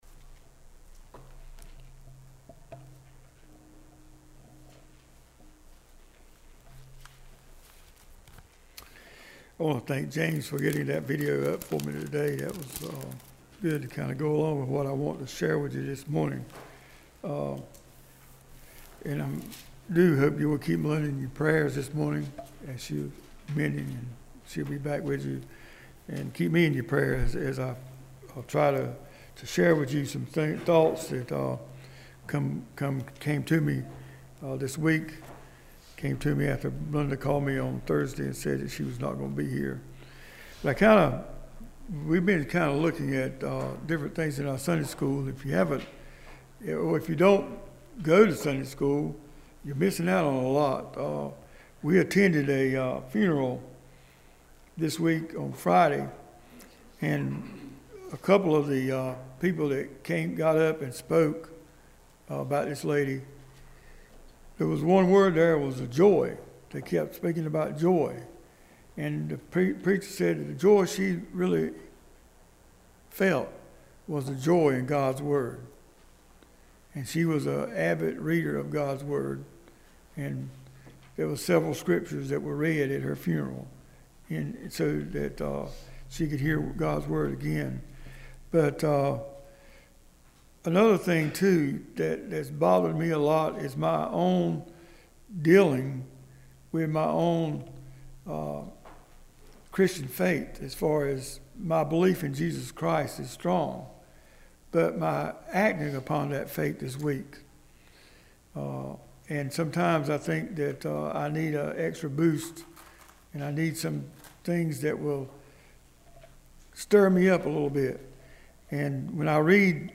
This weeks scripture and message: